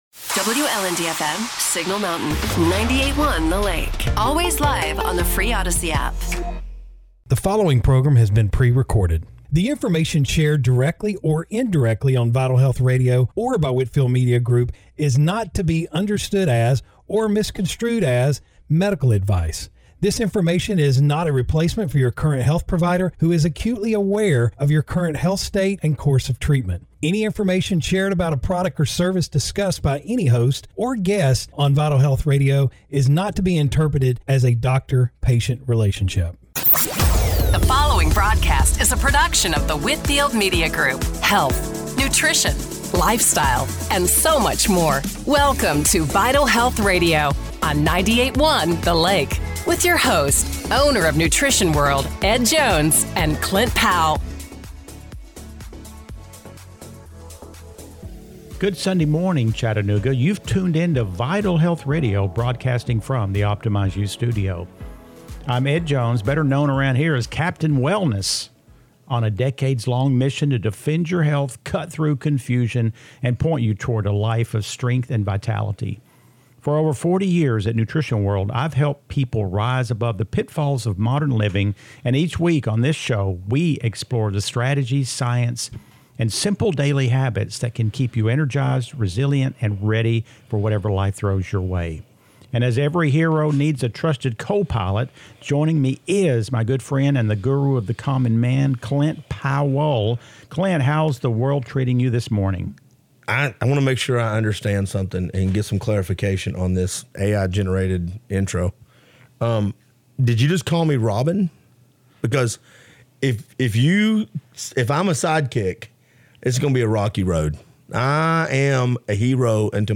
Broadcasting from the Optimize U Studio